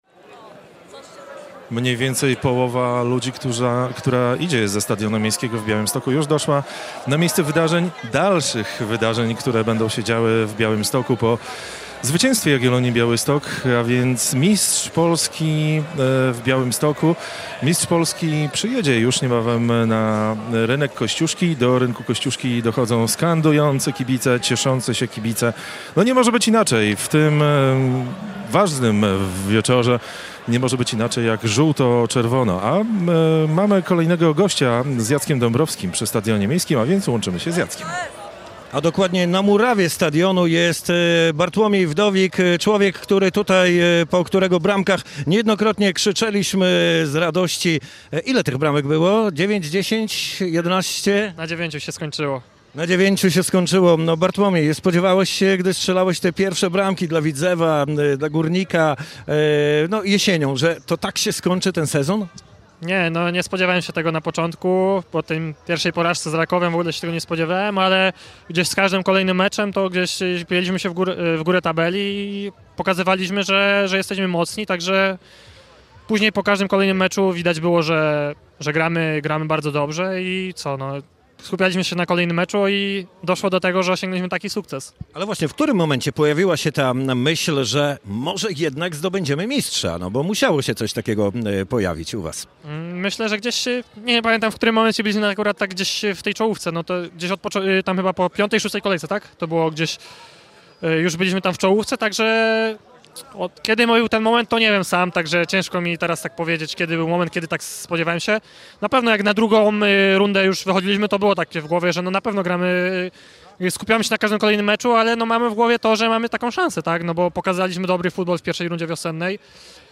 Z Bartłomiejem Wdowikiem rozmawia